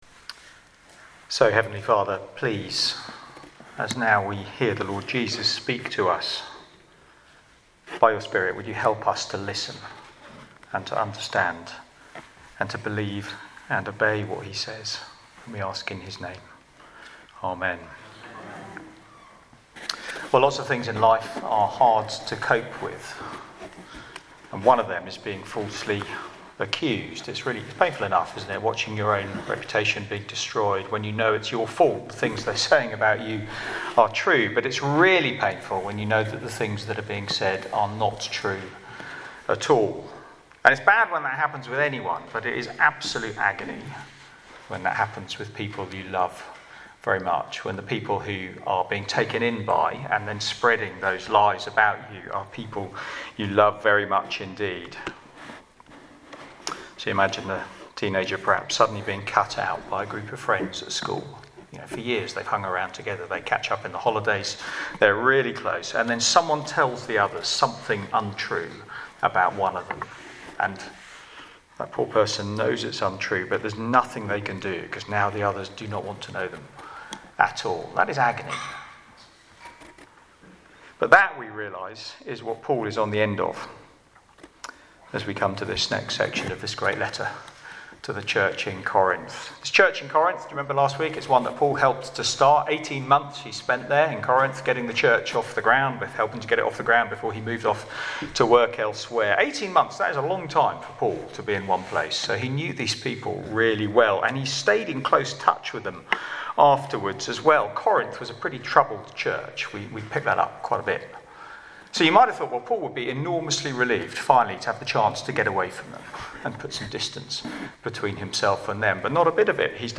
Media for Sunday Evening on Sun 17th Sep 2023 18:00
Sermon